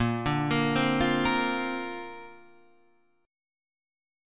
A#add9 chord {6 5 8 5 6 6} chord